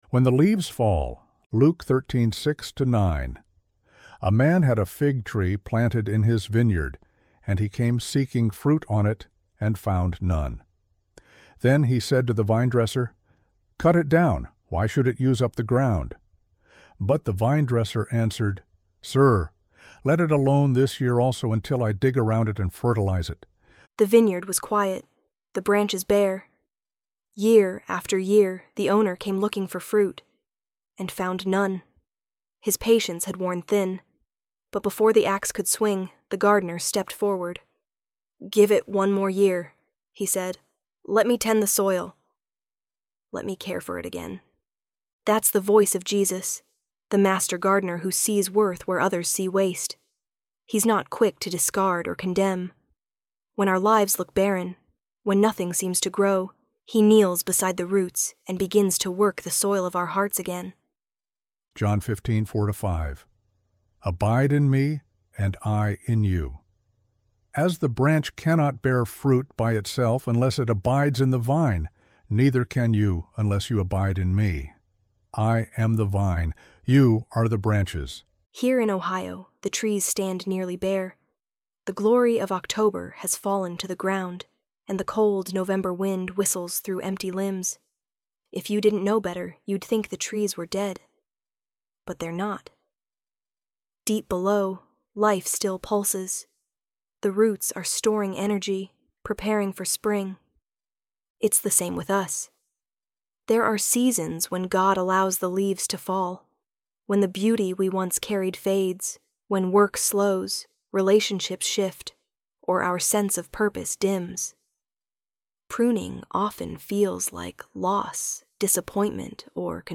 ElevenLabs_When_the_Leaves_Fall.mp3